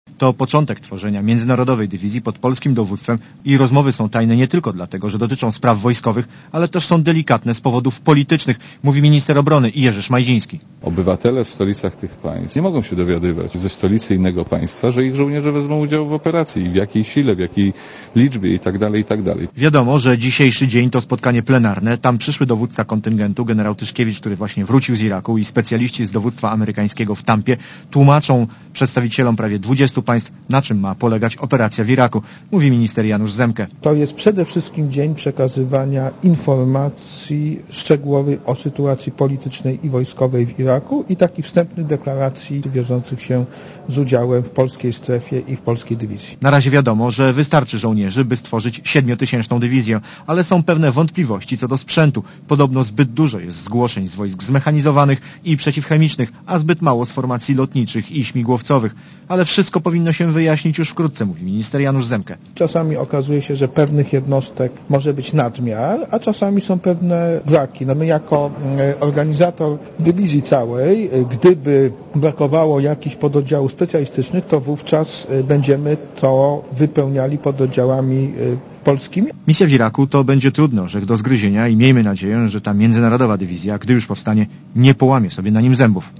Relacja reportera Radia Zet (335Kb)